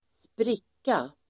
Uttal: [²spr'ik:a]